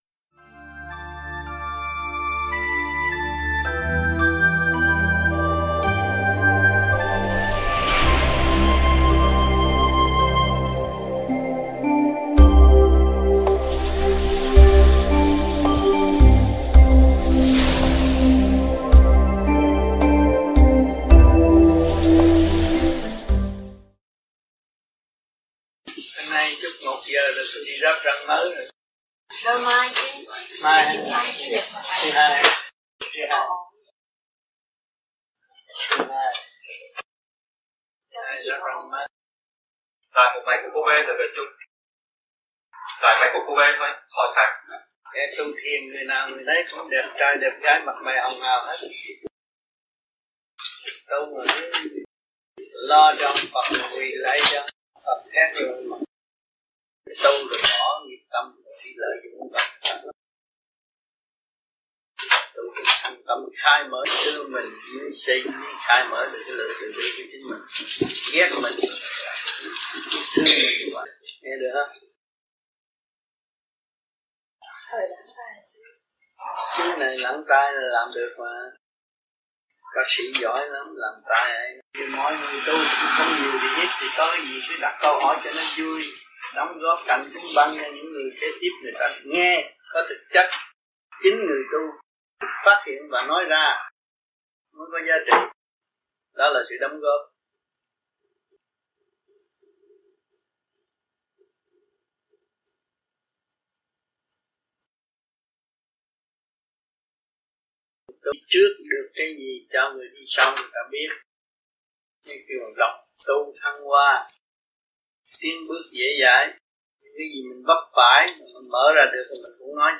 THUYẾT GIẢNG
VẤN ĐẠO